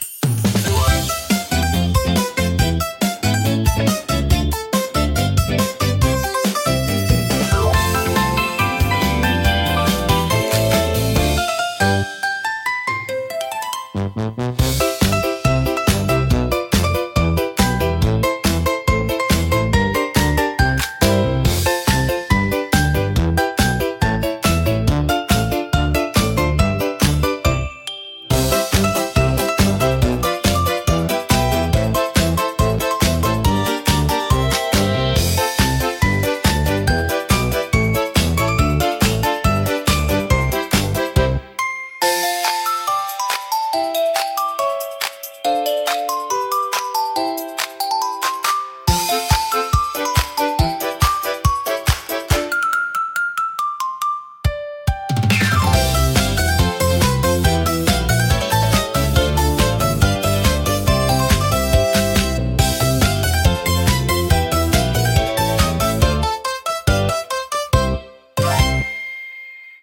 アクションは、明るくコミカルなシンセポップを特徴とするオリジナルジャンルです。
軽快なリズムと親しみやすいメロディーが、楽しく活発な雰囲気を作り出します。